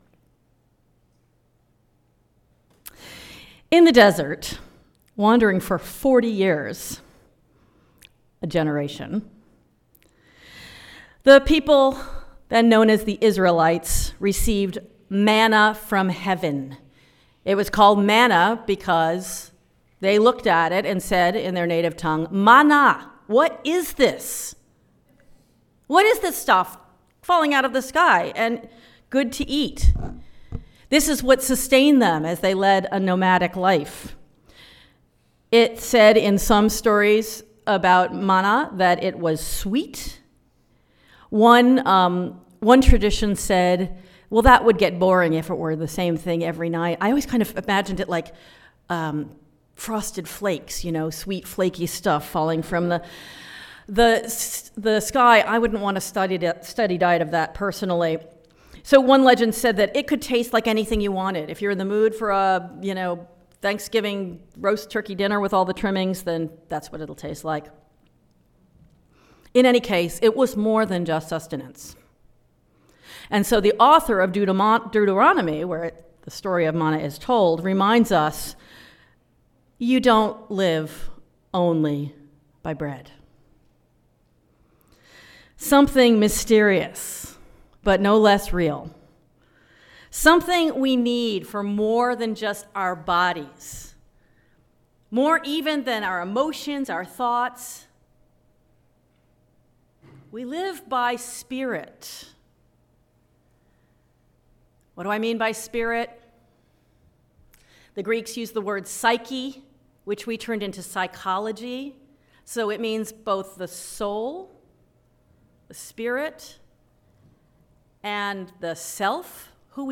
Sermon audio can be found on each service's page (select the service title below), followed by a video of the full service if available (starting April 12, 2020